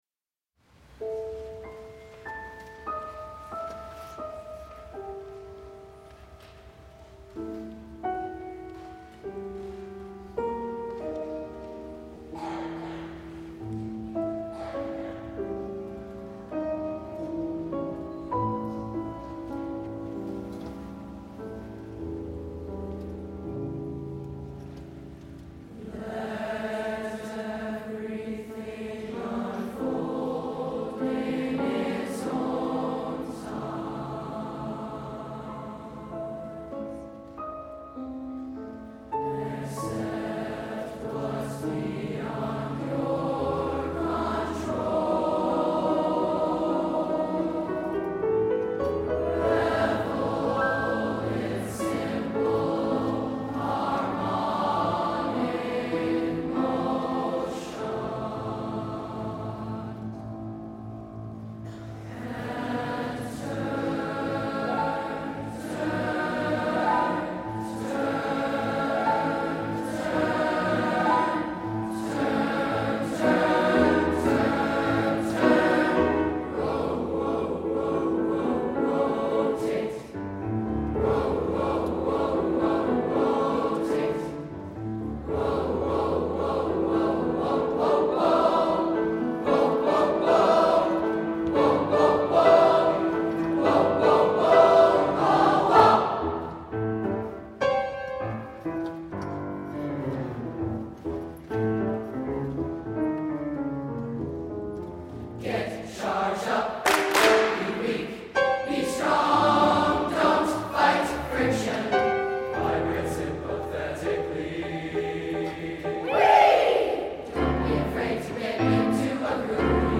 TBB, piano